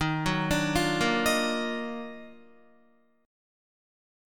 EbmM9 Chord
Listen to EbmM9 strummed